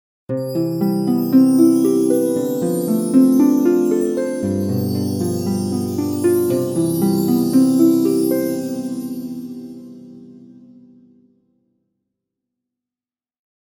Calm Gentle Transition Melody – Intro And Logo Music
Soft harp and gentle chimes create a simple, calming melody.
Genres: Sound Logo